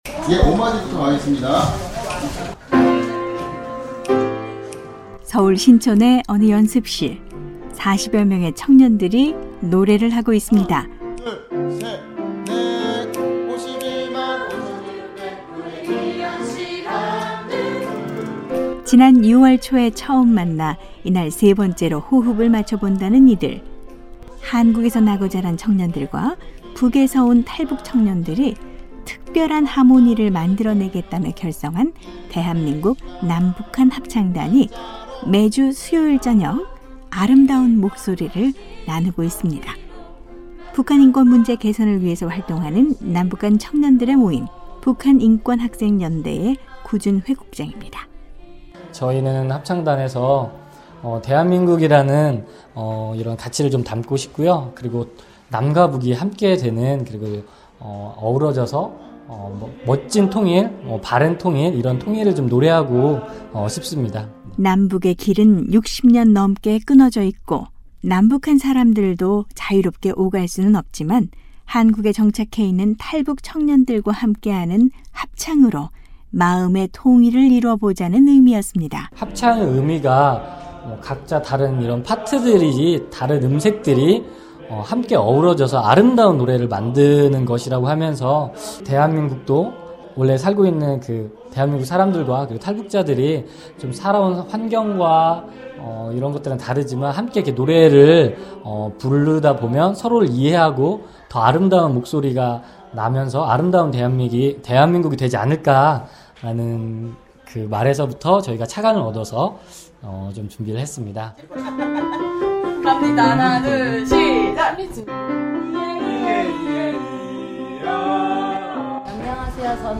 서로의 눈빛을 마주하며 박자를 맞추고 노래를 하는 ‘대한민국 남북한 합창단’의 통일하모니 현장으로 안내하겠습니다.